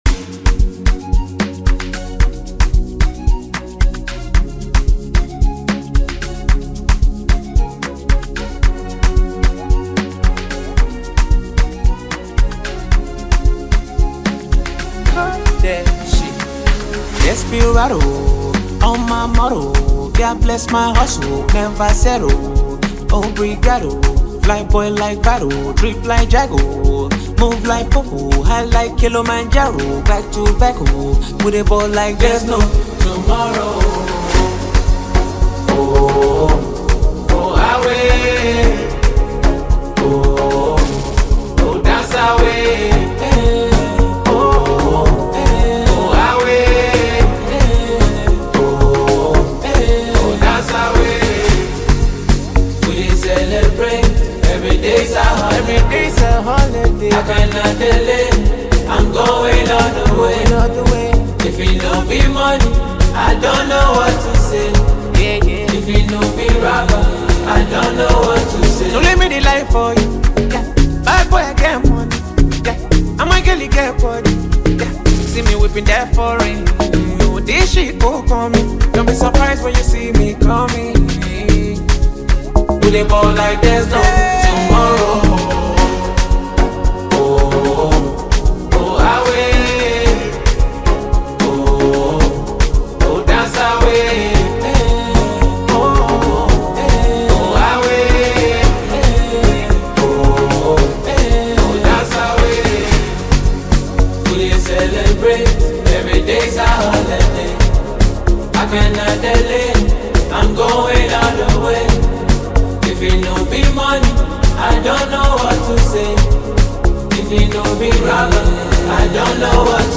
smooth and soulful vibe